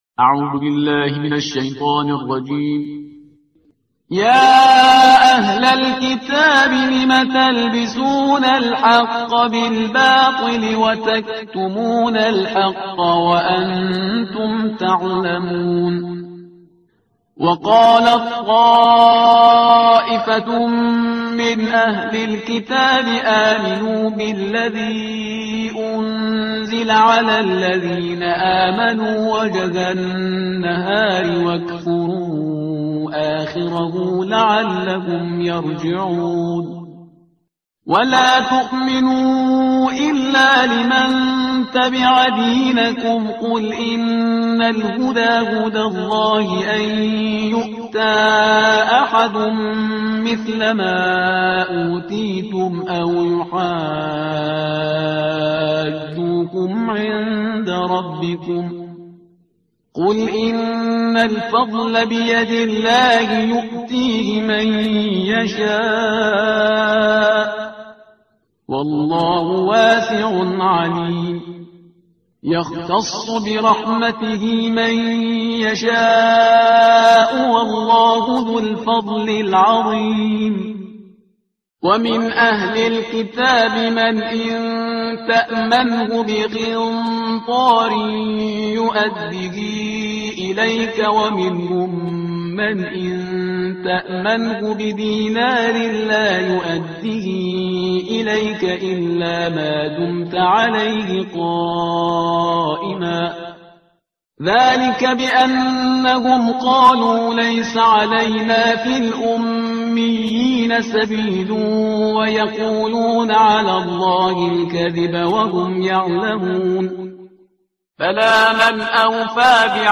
ترتیل صفحه 59 قرآن با صدای شهریار پرهیزگار